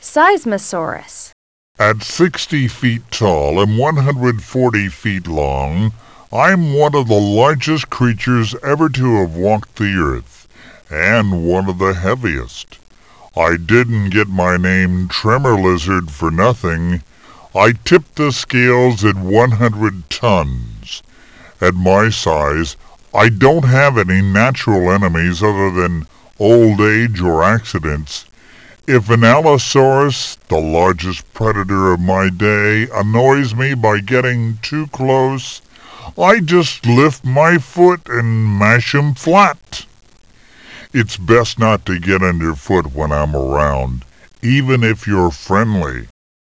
If Dinosaurs Could Talk
Learn about the dinosaurs listed by listening to them talk about themselves, that is... If Dinosaurs Could Talk.